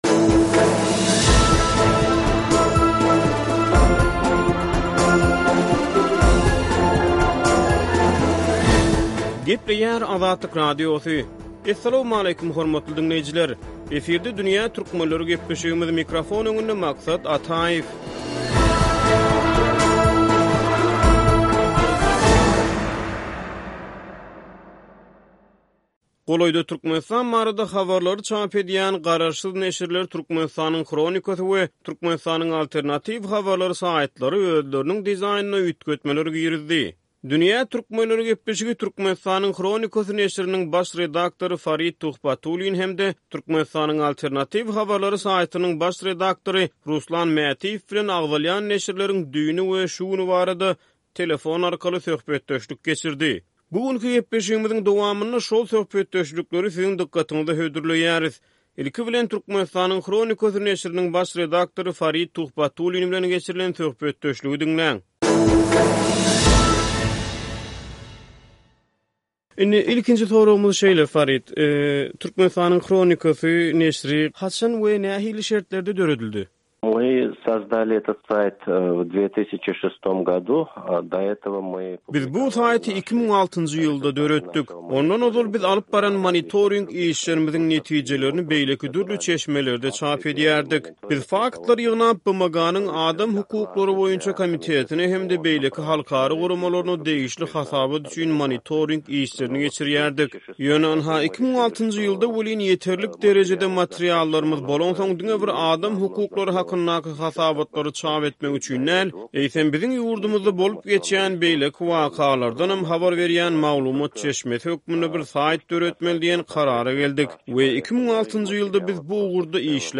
Garaşsyz neşirler bilen interwýu: Döwletiň 'gülala-güllük wagzyna' alternatiwa zerur